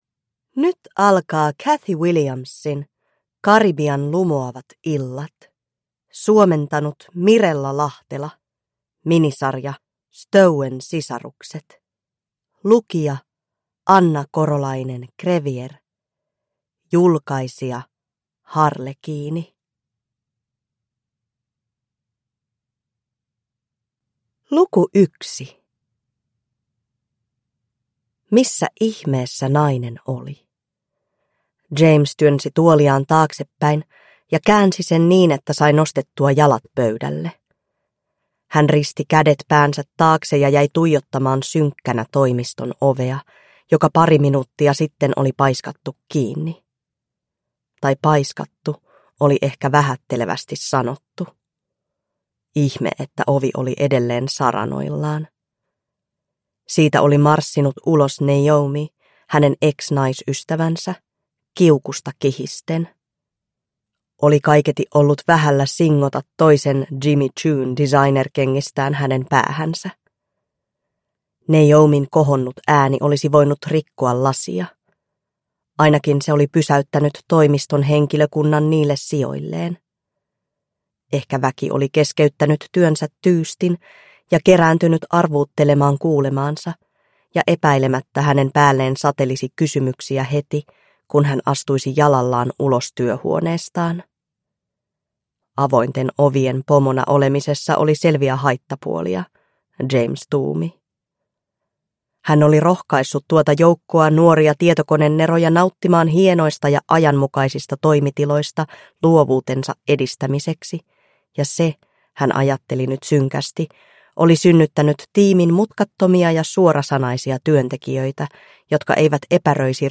Karibian lumoavat illat (ljudbok) av Cathy Williams